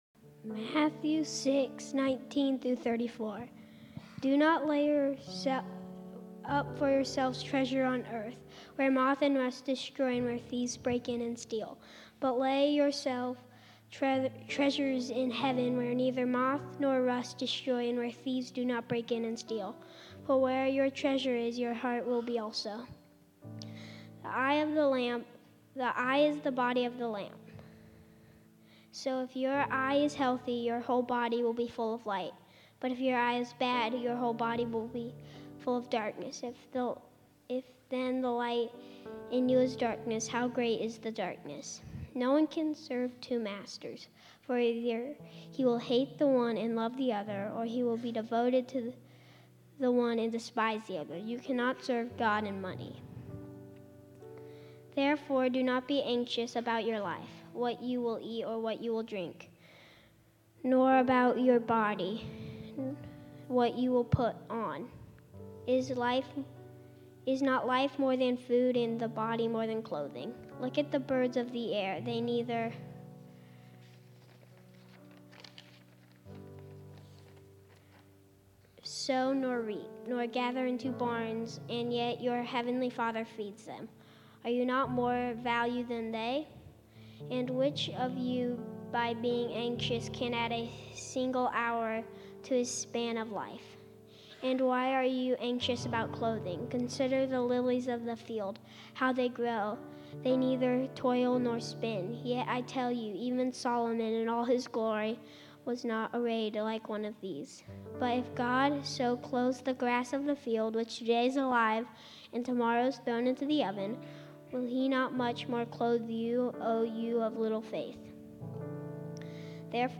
Grace Community Church Lindale Campus Sermons 3_16 Lindale Campus Mar 16 2025 | 00:30:45 1x 00:00 / 00:30:45 Subscribe Share RSS Feed Share Link Embed